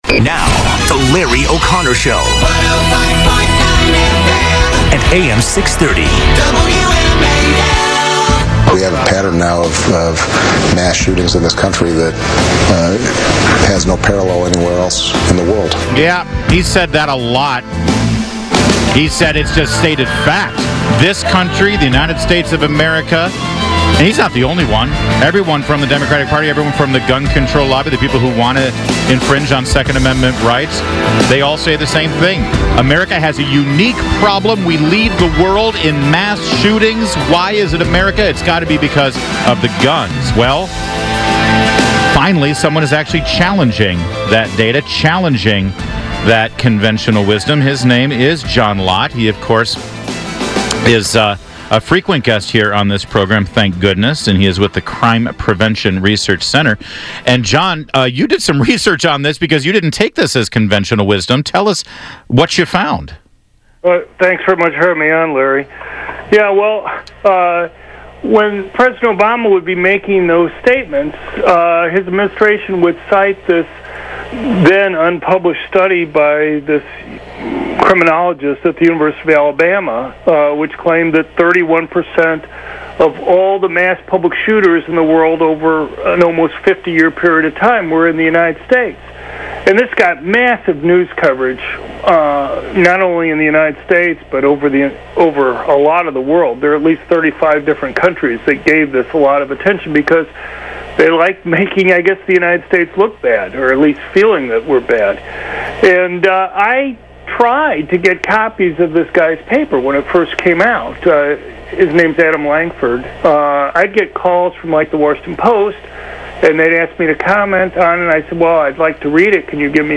Dr. John Lott talked to Larry O'Connor about our new research on how the US compared to other countries in terms of mass public shootings.
John_Lott_on_The_Larry_OConnor_Show_WMAL.mp3